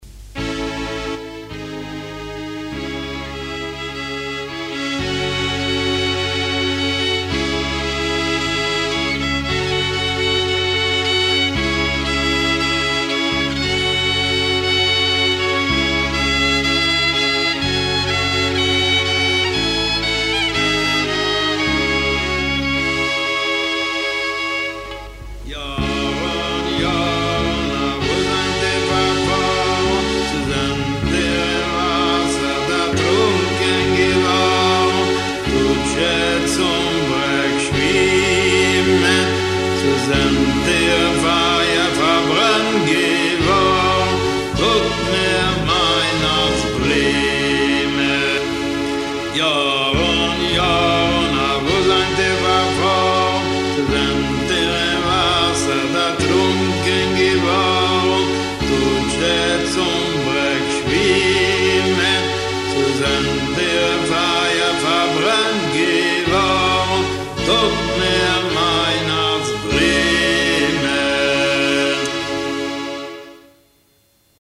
ניגוני דבקות  |  י׳ בשבט תשע״ז